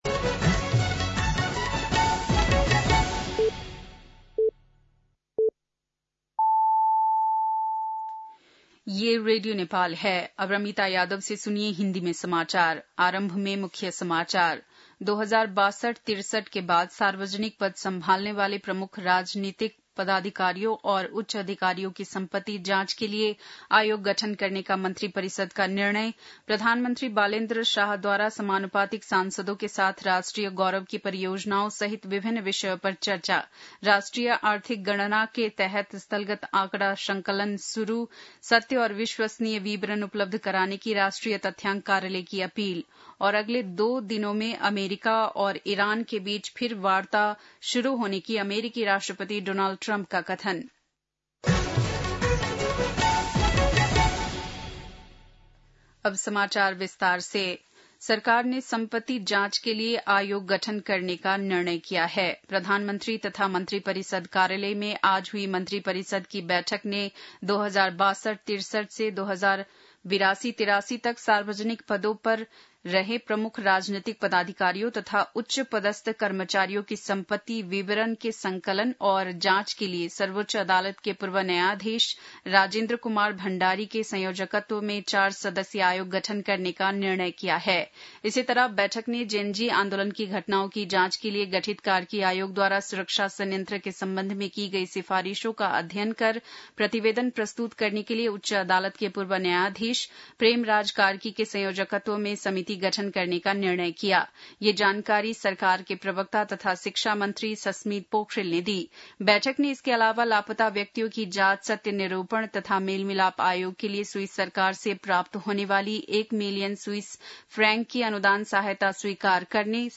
बेलुकी १० बजेको हिन्दी समाचार : २ वैशाख , २०८३
10-pm-hindi-news-1-02.mp3